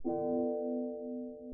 描述：模拟声音，吉他操作
Tag: 吉他 合成器